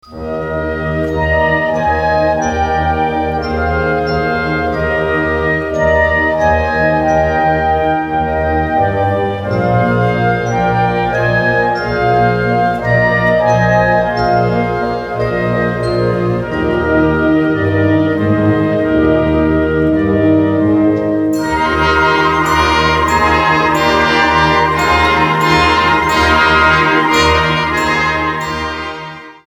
Catégorie Harmonie/Fanfare/Brass-band
Sous-catégorie Suite
Instrumentation Ha (orchestre d'harmonie)
Danses et chants